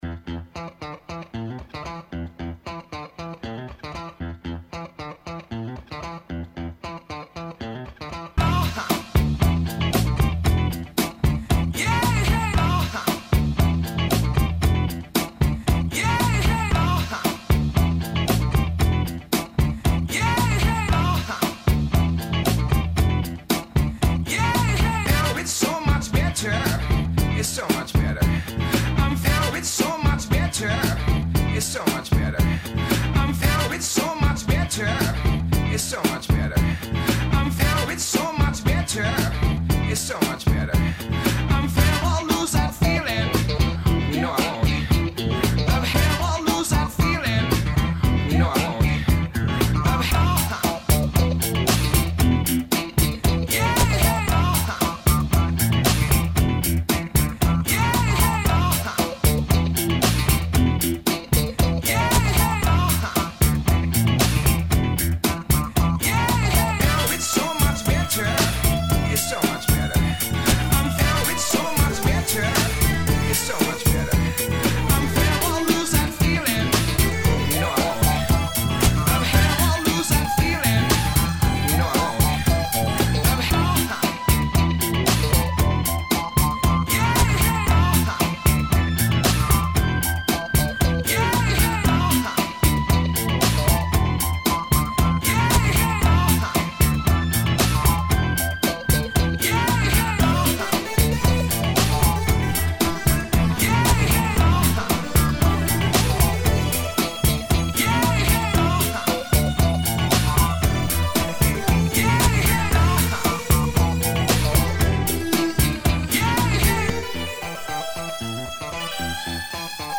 timing issues improved, more percussion, slightly longer
Genre Funk